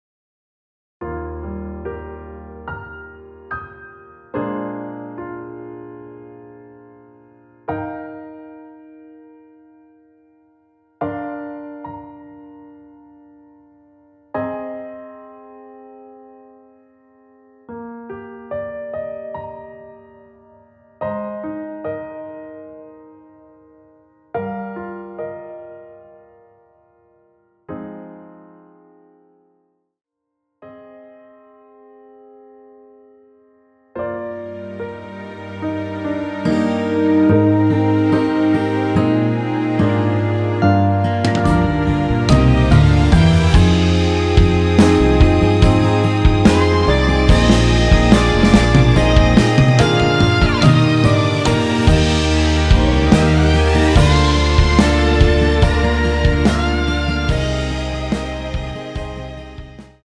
노래가 바로 시작 하는 곡이라 전주 만들어 놓았습니다.
6초쯤에 노래 시작 됩니다.(미리듣기 참조)
Eb
◈ 곡명 옆 (-1)은 반음 내림, (+1)은 반음 올림 입니다.
앞부분30초, 뒷부분30초씩 편집해서 올려 드리고 있습니다.
중간에 음이 끈어지고 다시 나오는 이유는